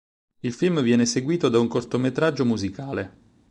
Read more music; musical Frequency C1 Hyphenated as mu‧si‧cà‧le Pronounced as (IPA) /mu.ziˈka.le/ Etymology From Medieval Latin mūsicālis, derived from Latin mūsica.